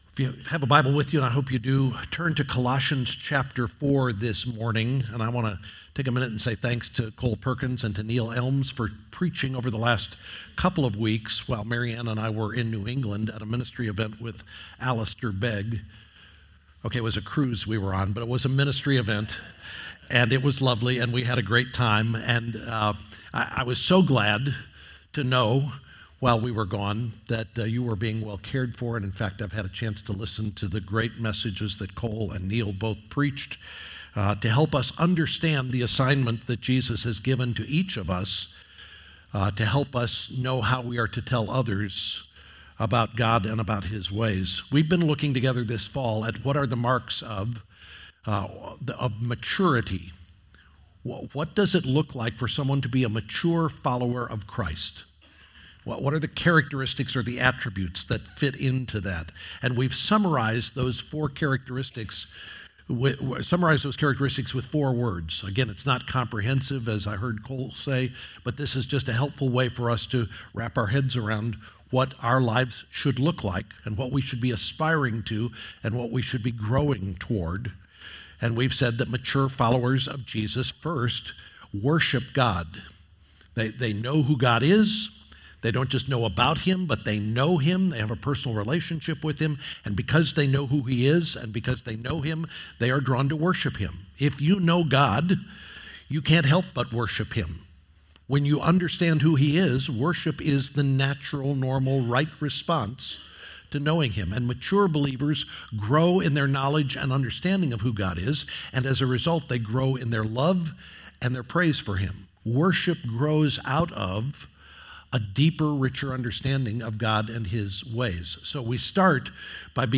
2024 Mature Disciples Colossians 4:2-6 The next sermon in our series about being a mature disciple where we think about what it means to tell others about Jesus with a prayer foundation and gracious and salty words that help others know who and what Jesus has done.